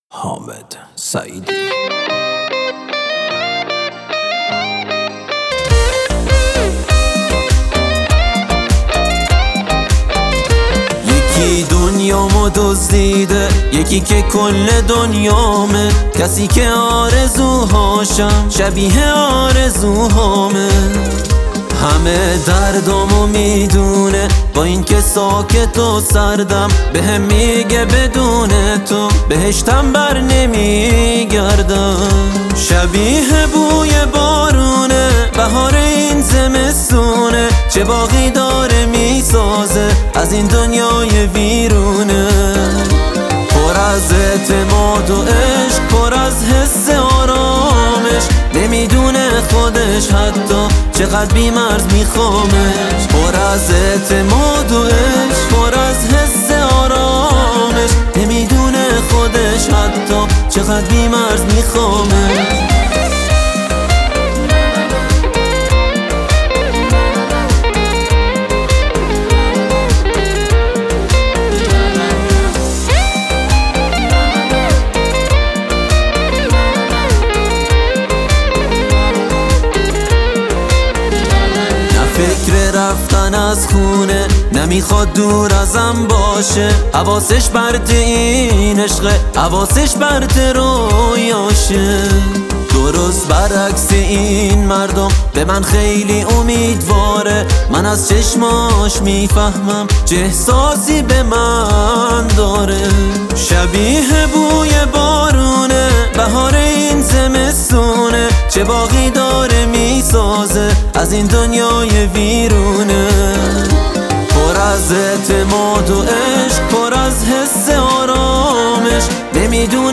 گیتار الکتریک